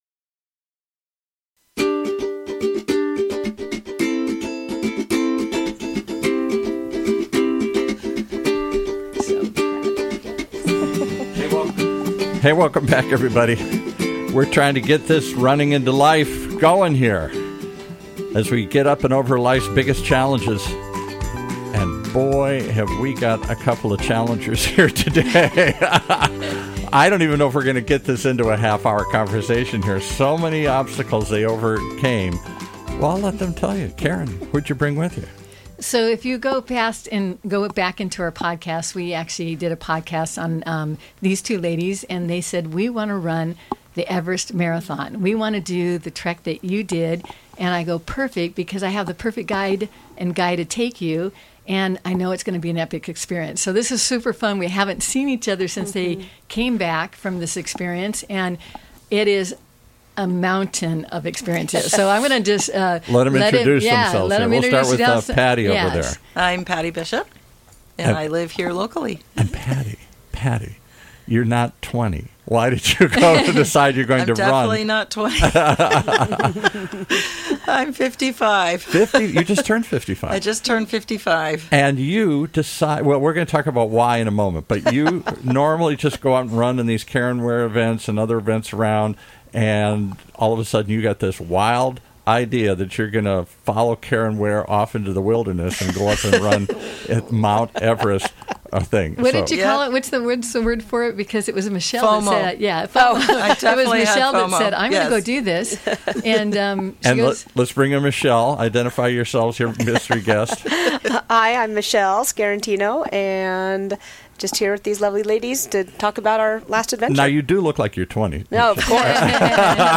Two of our regular runners